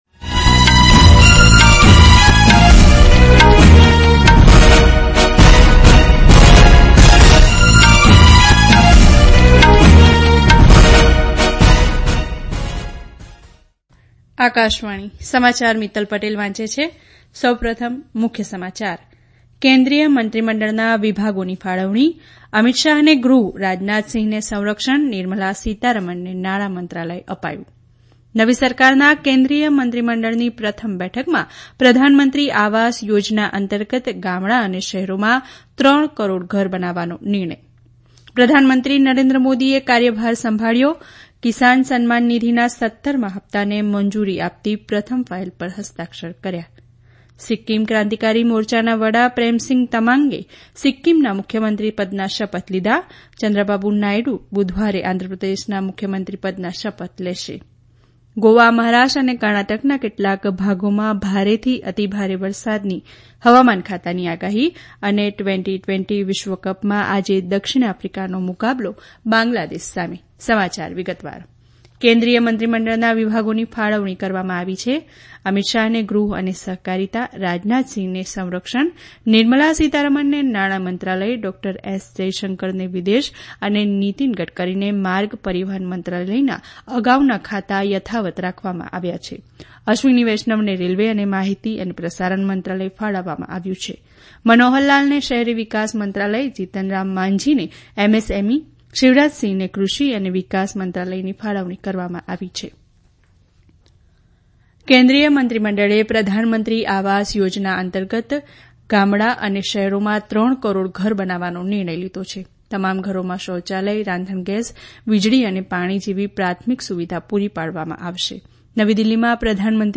Transcript summary Play Audio Morning News